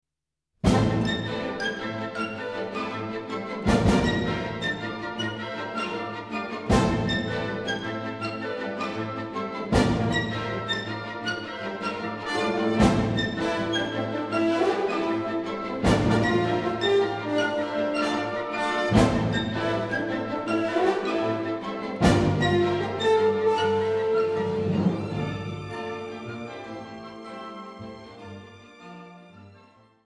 New Zealand Orchestral Music